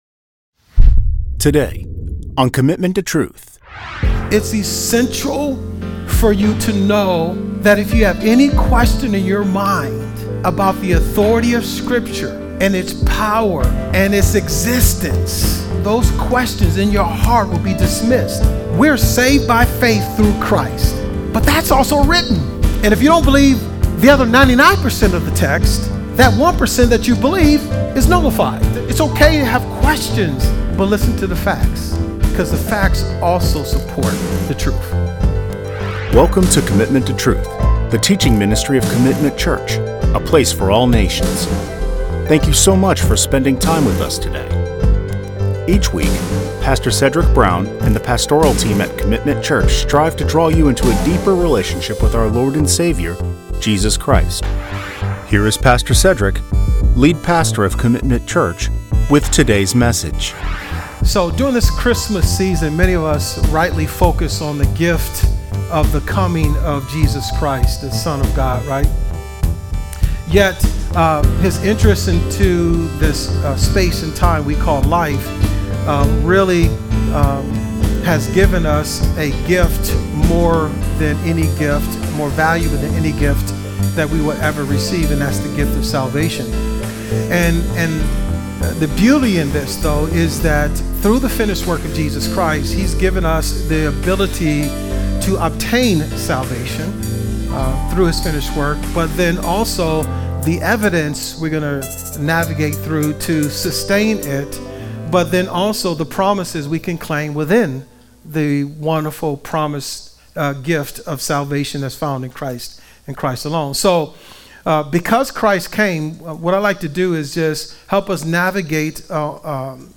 A message from the series "Because He Came."